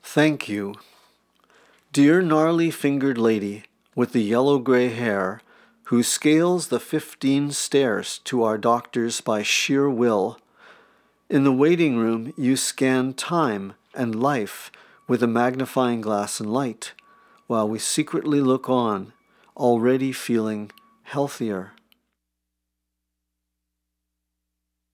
Poetry
reading 'Thank You' from 'Dungenessque' (0:25)